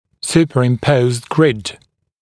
[ˌs(j)uːpərɪm’pəuzd grɪd][ˌс(й)у:пэрим’поузд грид]суперпозиционная сетка